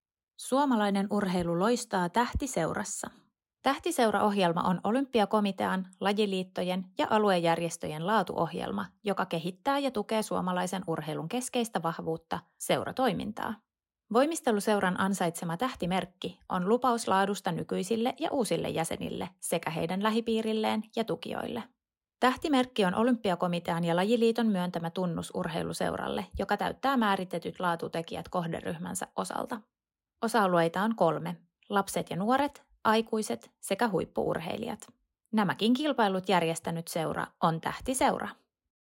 Äänimainos, jossa kerrotaan Tähtiseuraohjelmasta.